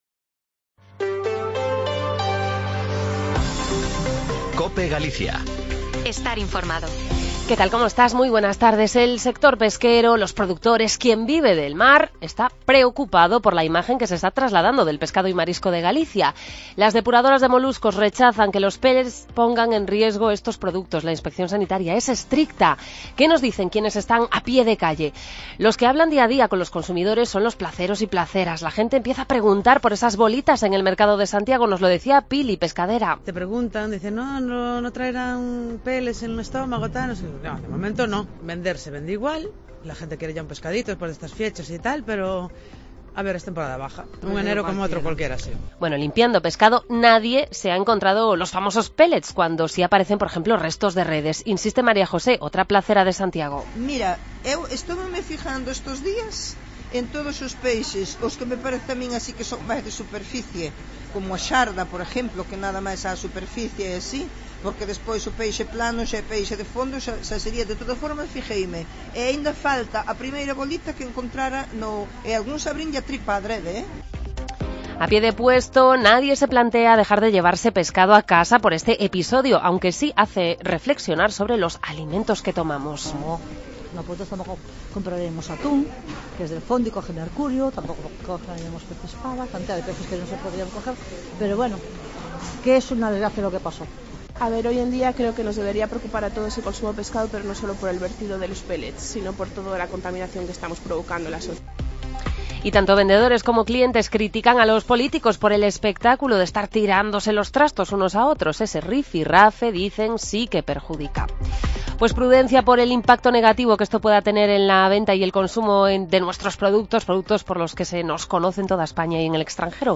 ¿Preocupa en el sector pesquero y en el consumo el vertido de pellets? Nos acercamos a la plaza de Abastos de Santiago para recoger opiniones de vendedores y clientela. También te contamos cómo se realizan las mediciones de ruidos: esta semana se ha constituido la Federación Galega contra a contaminación acústica.